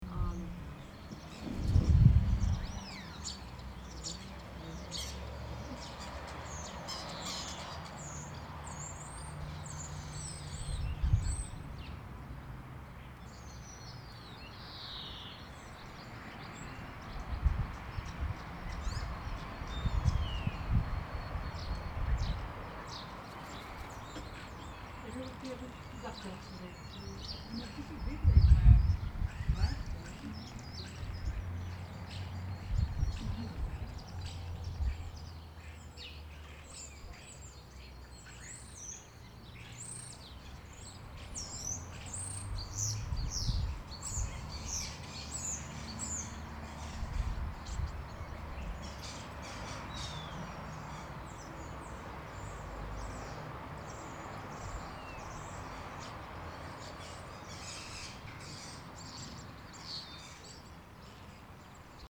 Nous profitons des derniers rayons du soleil en écoutant les oiseaux et les voitures.